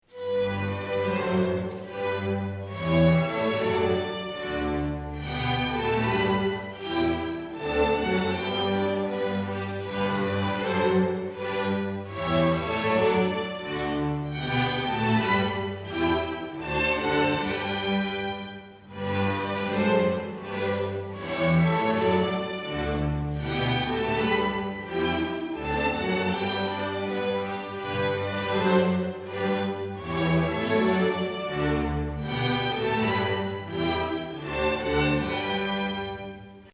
2015-10-18 Konsert Längbro kyrka tillsammans med Kumla Hallsbergs orkesterförening